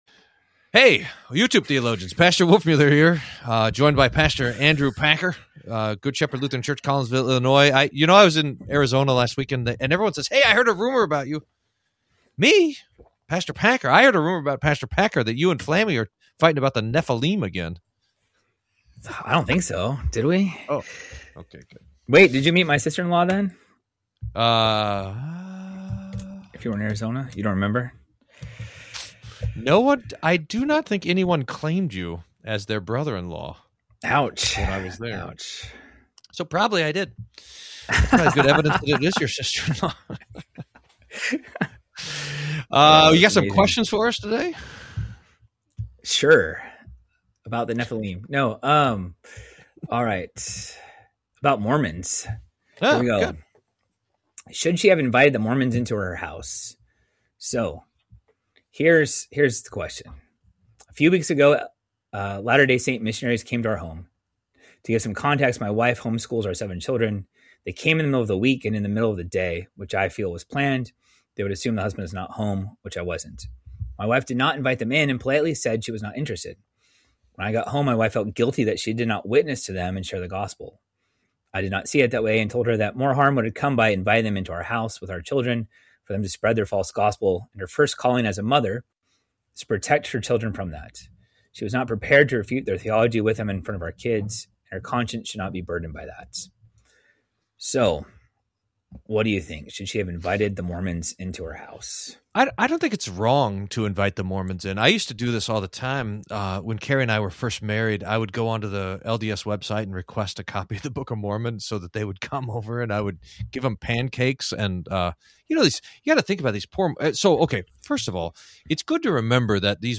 Q&A: Should you invite the Mormons inside and evangelize to them? Is the Lord's Supper Cannibalism? How should Christians respond to superstitions? More.